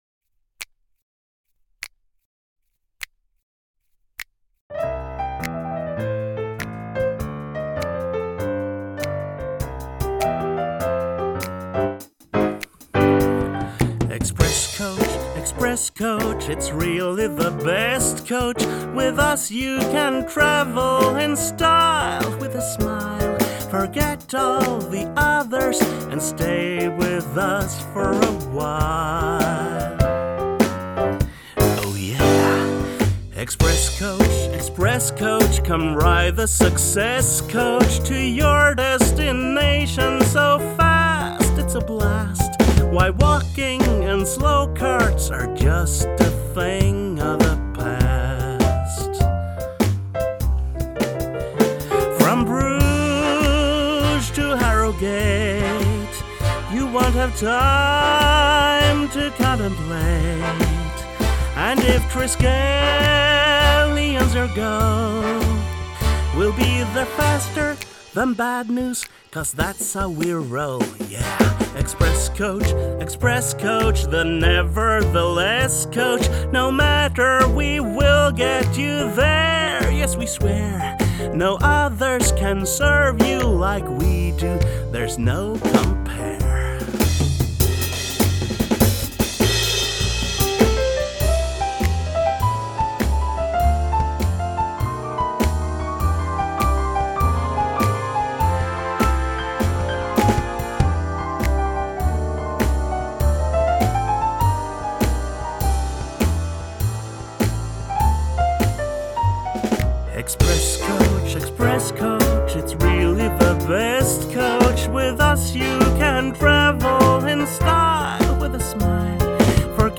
Style: Jazz
Jazzy short showtune